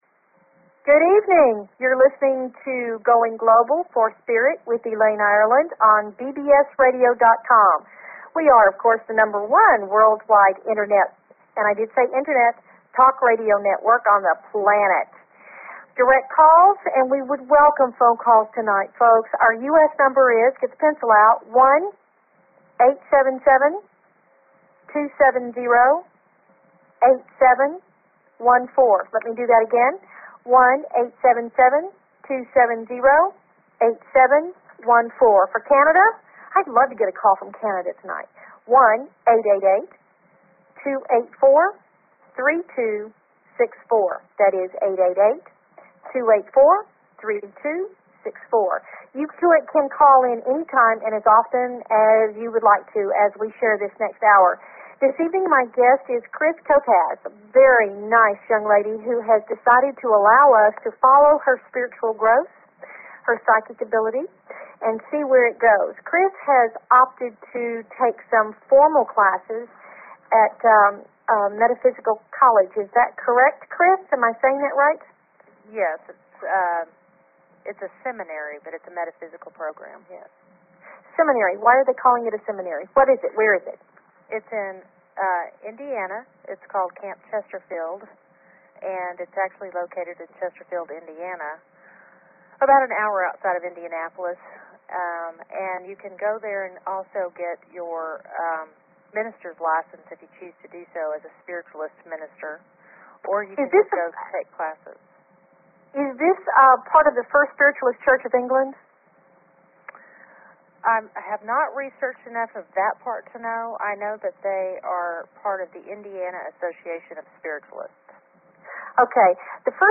Talk Show Episode, Audio Podcast, Going_Global_for_Spirit and Courtesy of BBS Radio on , show guests , about , categorized as
They invite you to call in with your questions and comments about everything metaphysical and spiritual!"